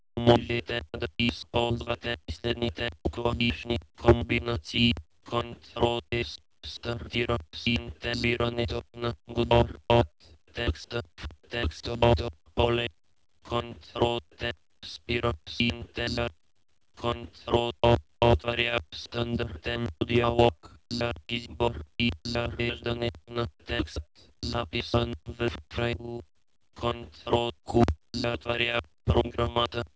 - An audio file, you can listen how FWTalker reads the first part of the FWTalker help.